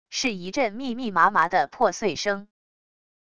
是一阵密密麻麻的破碎声wav音频